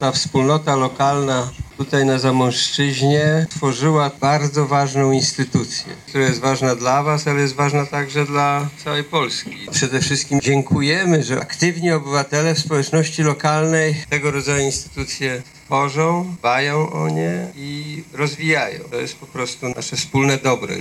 W oficjalnym otwarciu uczestniczył wicepremier i minister kultury Piotr Gliński.
Obiekt otrzymał 360 tysięcy złotych dofinansowana z programu ministra kultury i dziedzictwa narodowego. – Wsparcie muzeum było dla rządu obowiązkiem. Wspólnota lokalna na Zamojszczyźnie stworzyła bardzo ważną instytucję. Jest ona ważna dla was, ale także dla całej Polski – mówił wicepremier Piotr Gliński.